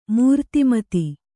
♪ mūrtimati